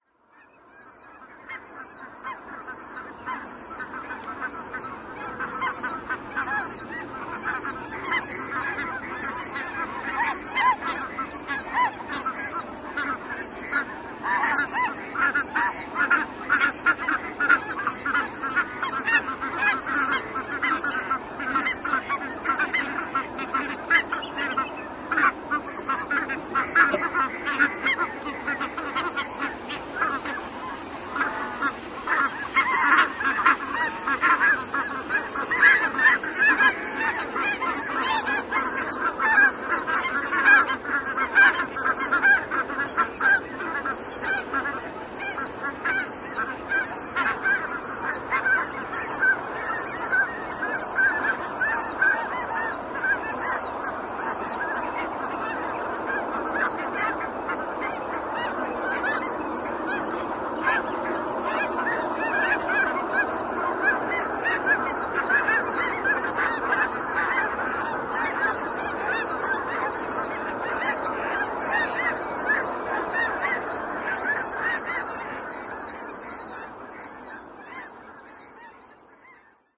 噼里啪啦的电声和高亢的音调
描述：另一个电噼啪声。灵感再次来自火影忍者动漫和chidori的声音。口口相传：D
标签： 噼啪 千鸟 电气 raikiri 火花 闪电 电力
声道立体声